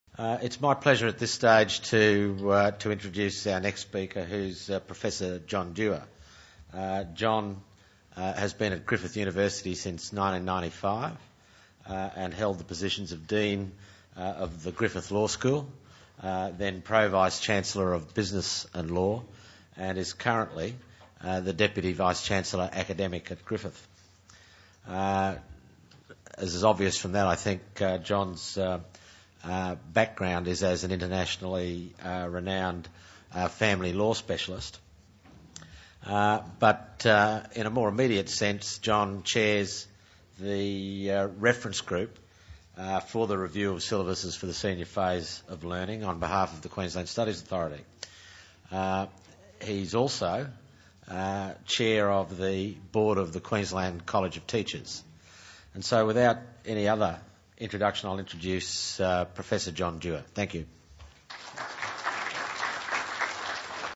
The syllabus review colloquium — A proposed blueprint for the future development of syllabuses: fields of learning and study patterns — was held in Brisbane on 21 November 2006.